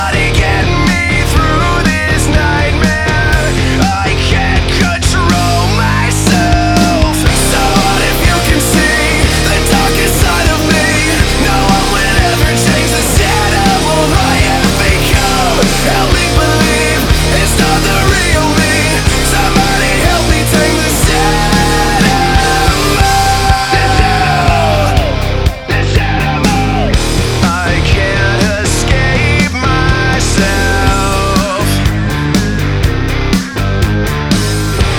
Hard Rock Rock Alternative Metal
Жанр: Рок / Альтернатива / Метал